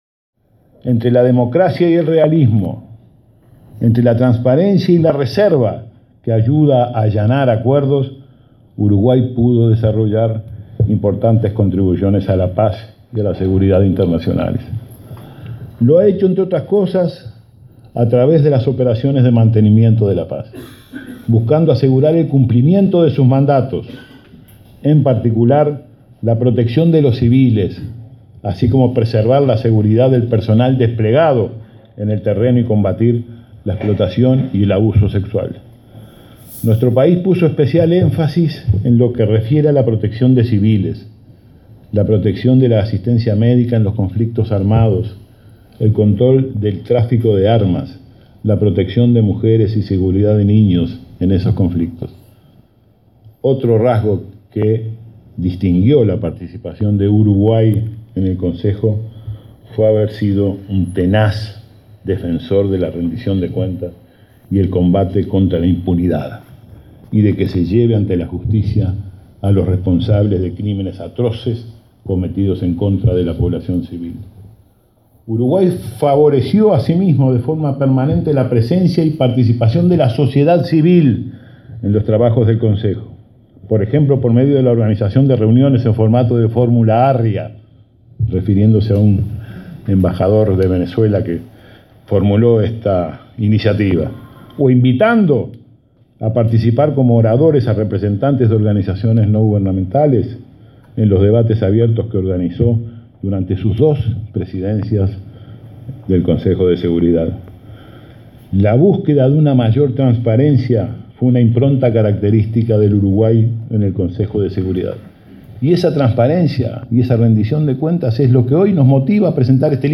El canciller Rodolfo Nin Novoa destacó la actuación de Uruguay en el Consejo de Seguridad de la ONU, en la presentación de una publicación sobre su accionar durante 2016-2017. Hizo énfasis en la protección de civiles, la asistencia médica en conflictos armados, el control de tráfico de armas, la protección de mujeres y niños, el juicio a los responsables de crímenes contra civiles y la búsqueda de la transparencia.